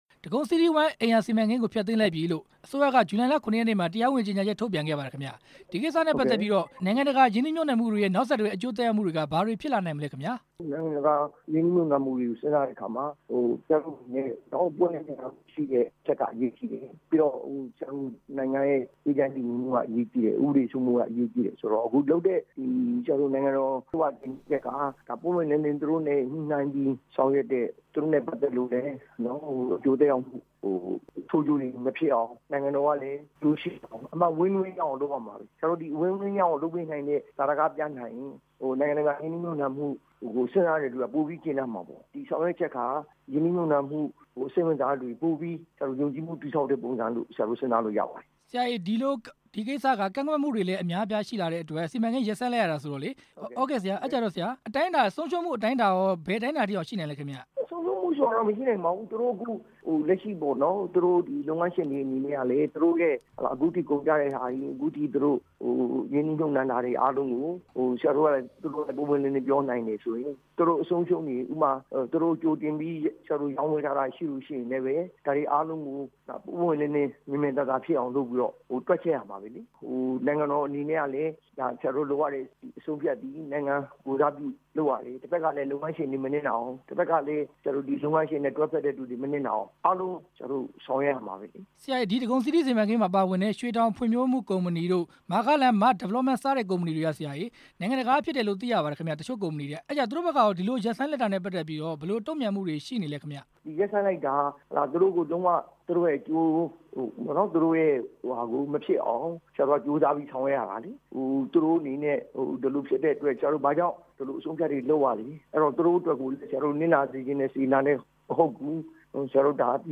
ဒဂုံစီးတီးဝမ်း စီမံကိန်း အကျိုးသက်ရောက်မှု မေးမြန်းချက်